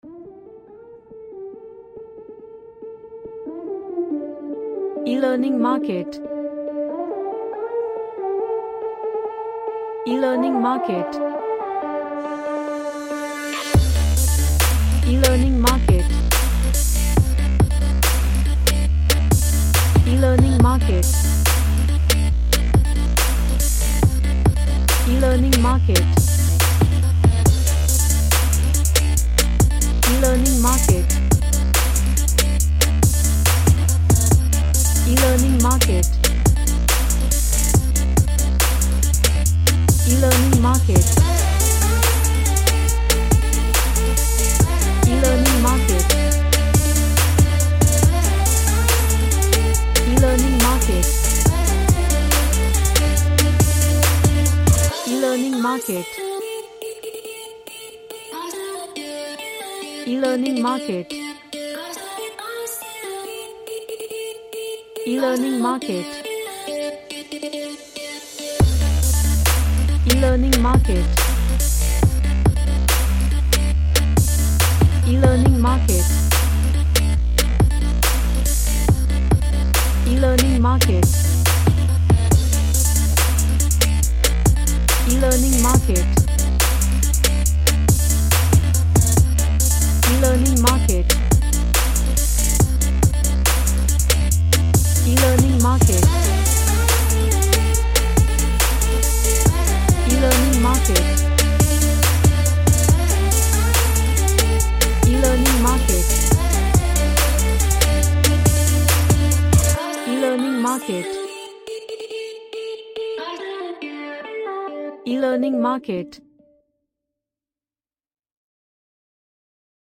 A futuristic track with tight drums
Sci-Fi / Future